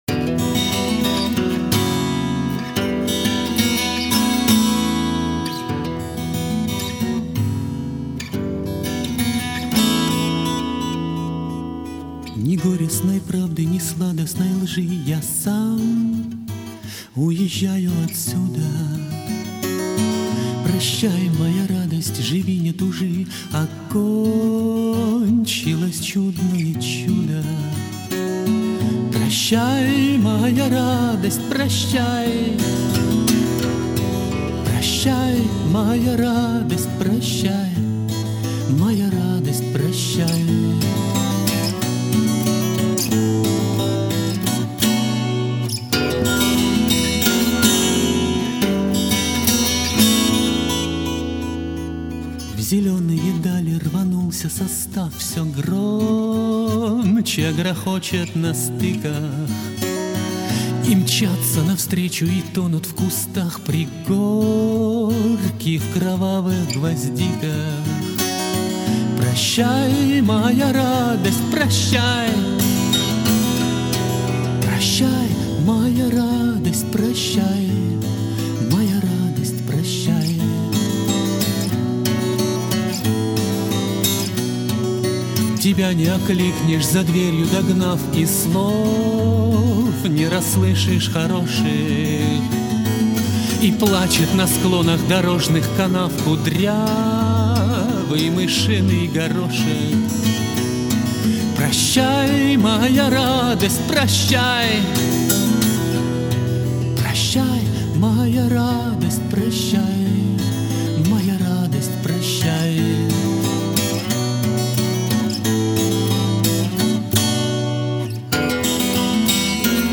Новое слово в гитарной авторской песне
Записано в концертном варианте 27 августа 2000 года.
"Арктик-Студио" г. Архангельск